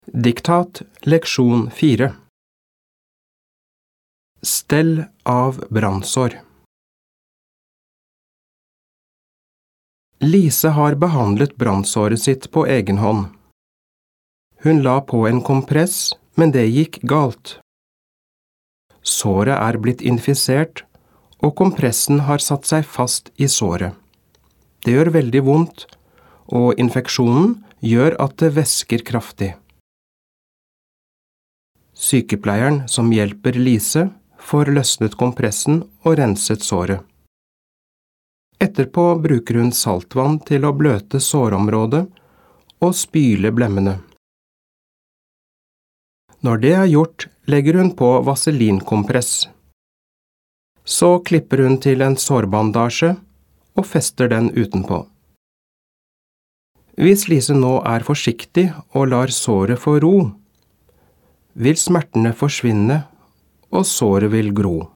Diktat leksjon 4
• Første gang leses hele teksten, og du skal bare lytte.